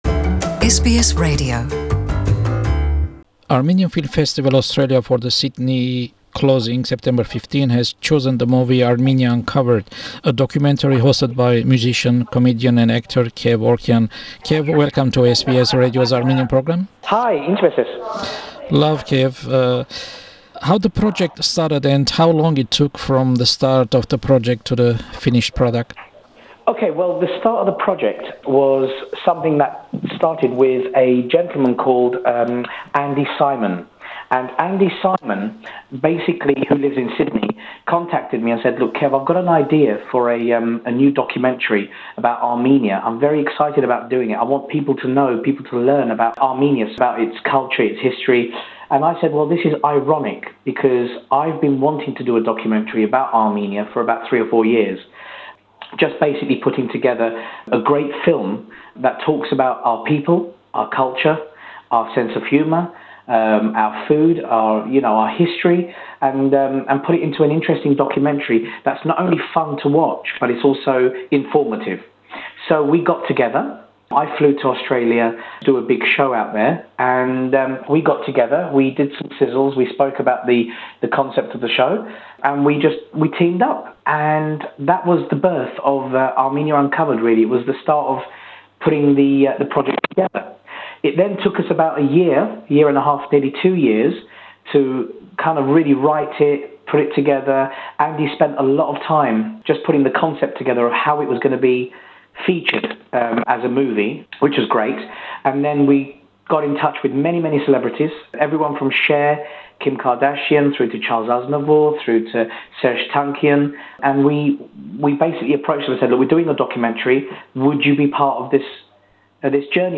Interview with musician, comedian and actor Kev Orkian about his visit to Sydney on the occasion of the showing of his documentary “Armenia Uncovered” at the closing of the Armenian Film Festival this weekend.